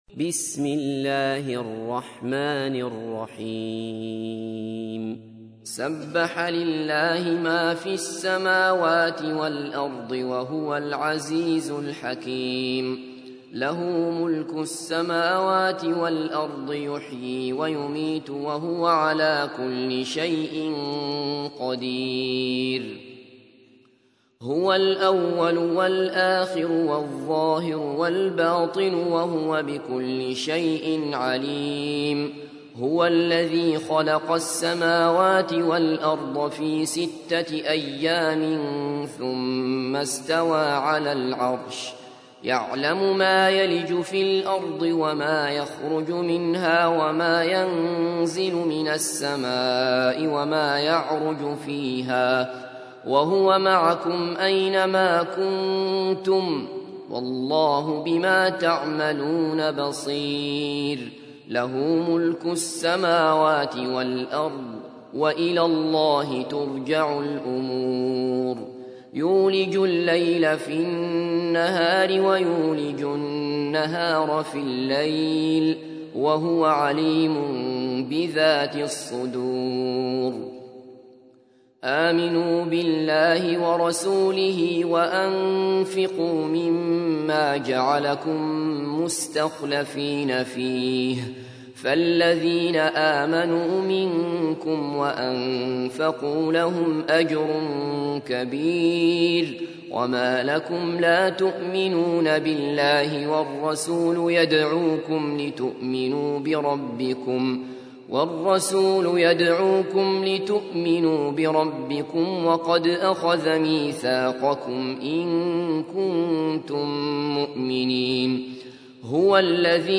تحميل : 57. سورة الحديد / القارئ عبد الله بصفر / القرآن الكريم / موقع يا حسين